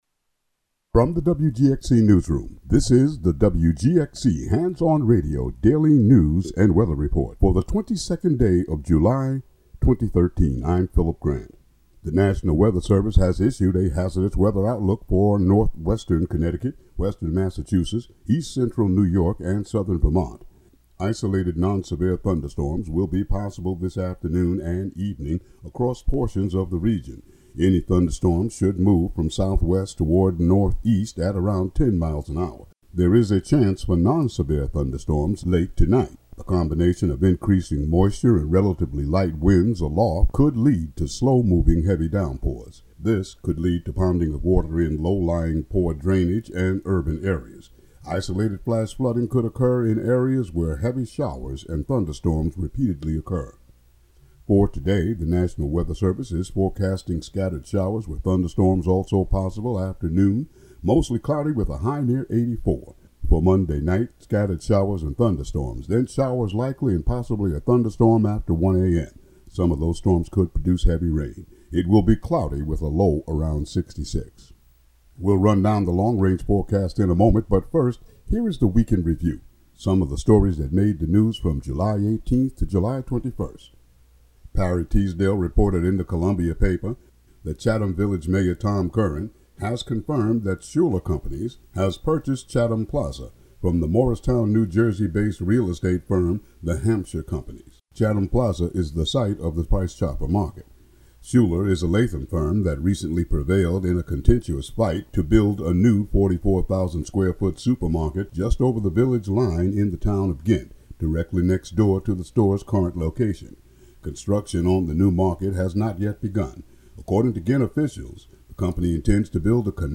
Local news and weather for Monday, July 22, 2013.